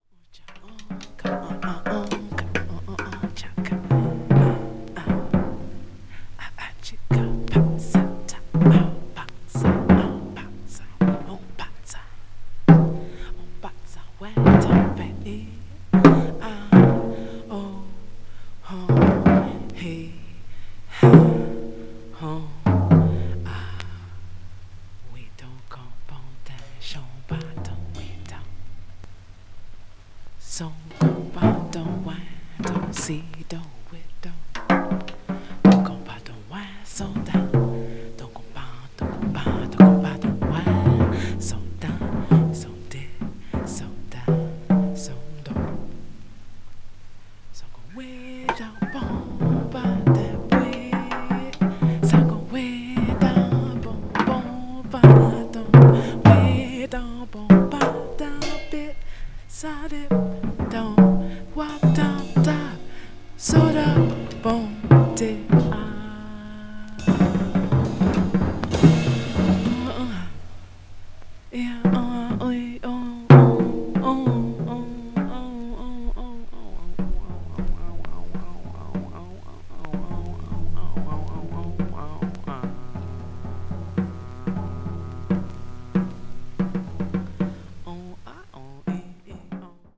avant-jazz   ethnic jazz   free improvisation   free jazz